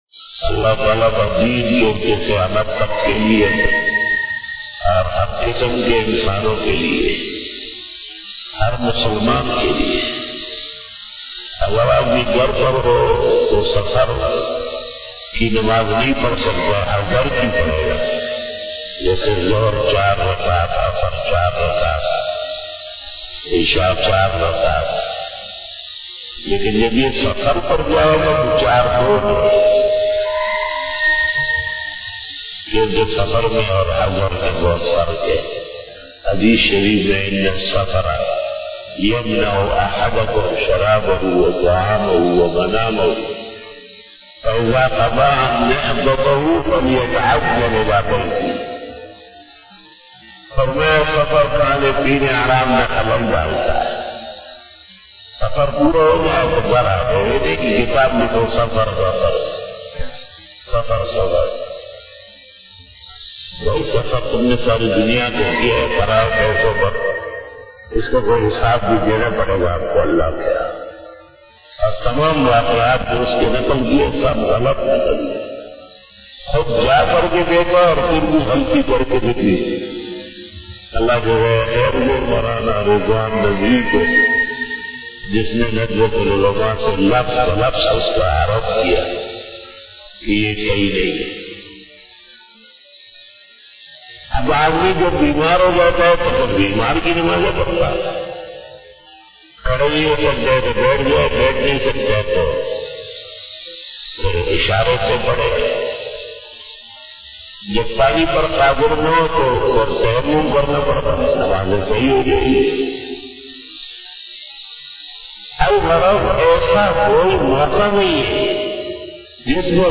After Fajar Byan
بیان بعد نماز فجر بروز اتوار